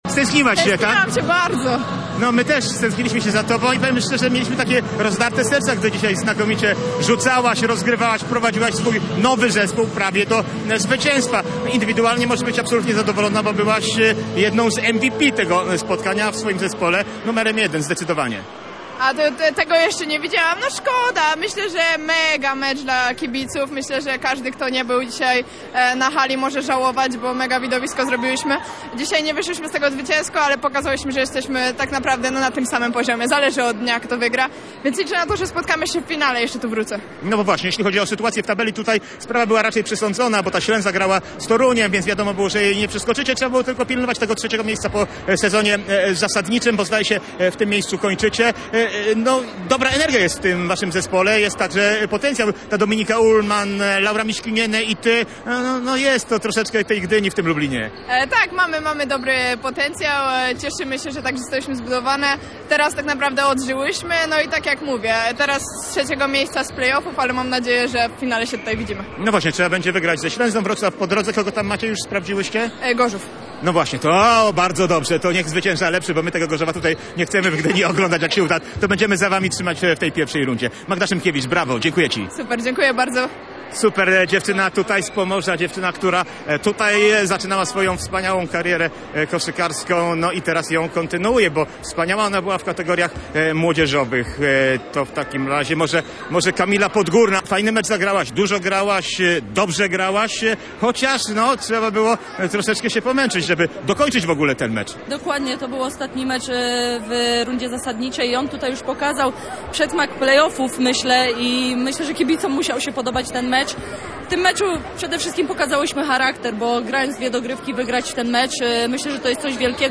na żywo w audycji „Z boisk i stadionów”